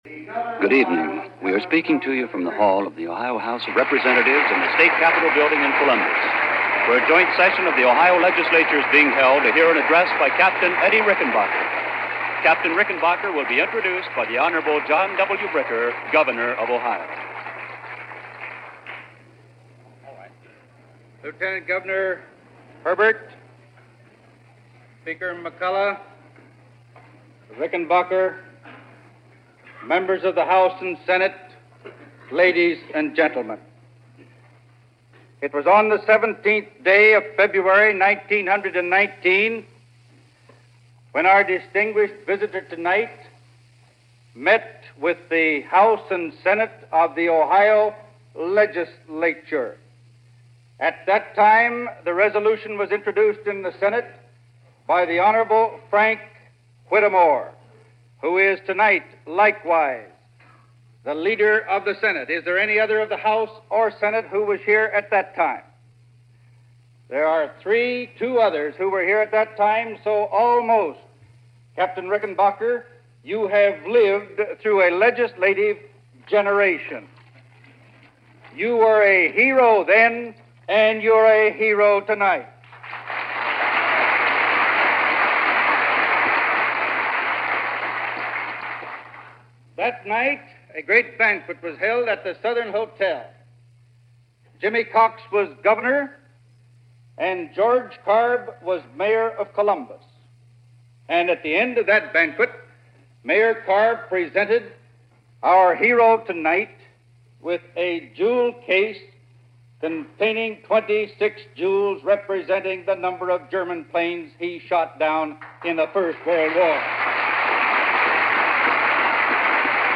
Captain Eddie Rickenbacker Addresses The Ohio Legislature - April 12, 1943 - Past Daily After Hours Reference Room